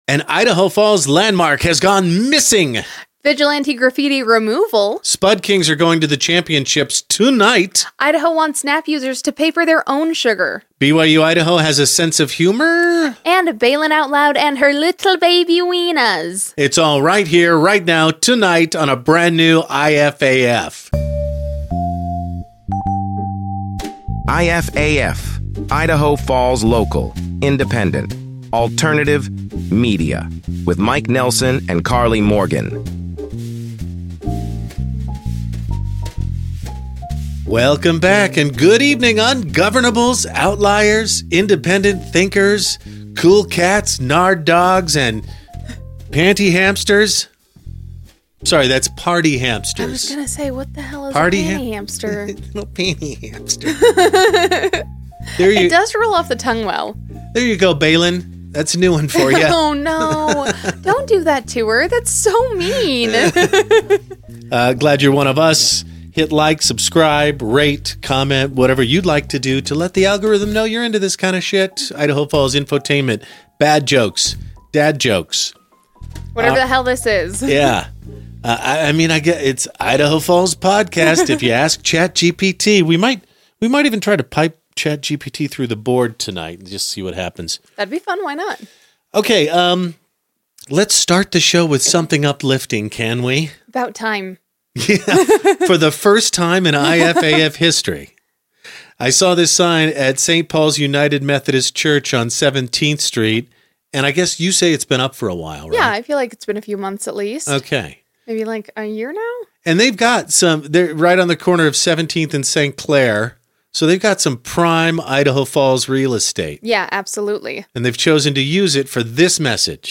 Join us for an exclusive interview